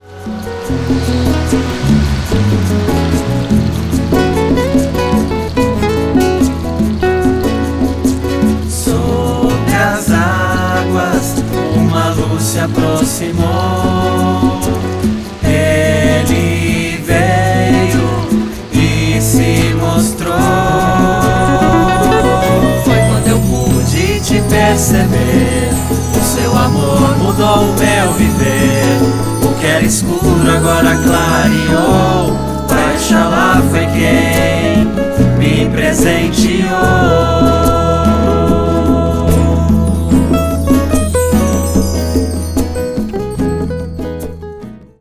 Backing Vocals
Violões
Percussão
Ganzá
Efeitos